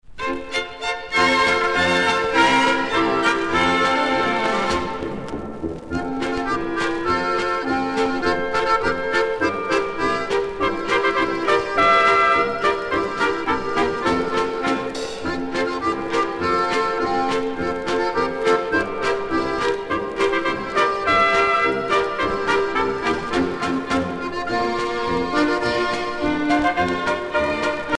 danse : polka